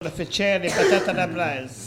Soullans
Locutions vernaculaires